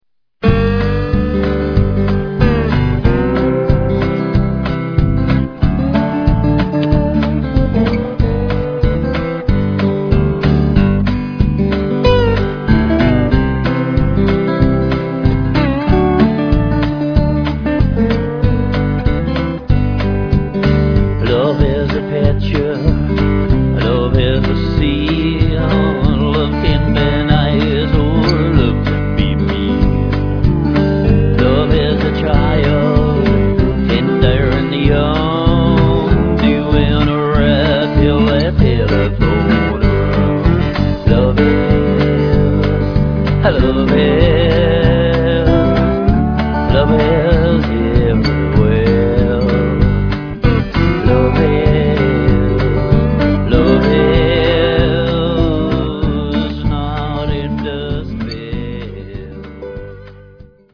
Intro. G-C-D-G x 2
Lead Break as per verse and fade away